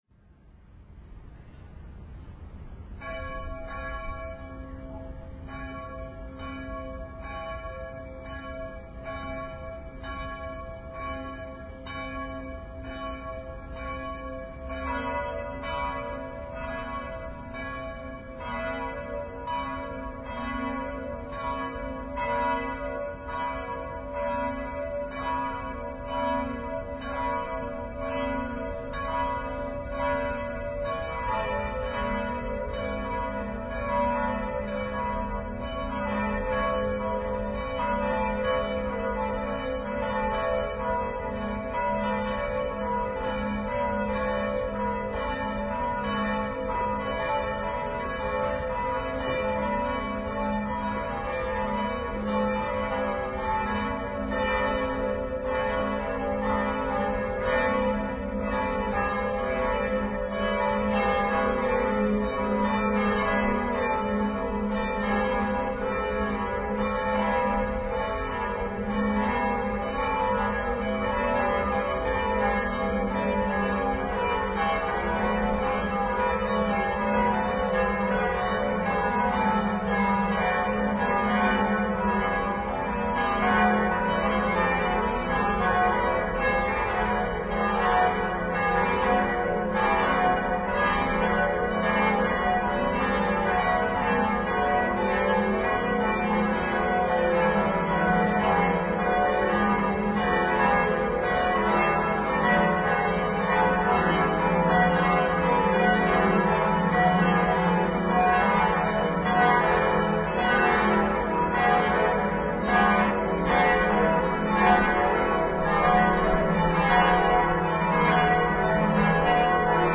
Click here to hear the cathedral bells
DomGlocken24.mp3